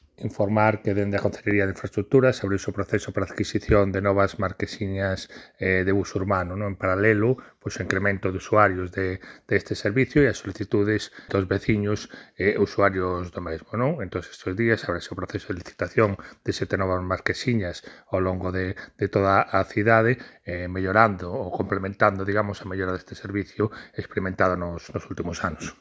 Audio O concelleiro de Infraestruturas Urbanas, Alexandre Penas, sobre as marquesinas do bus | Descargar mp3